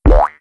MonsterSkill.wav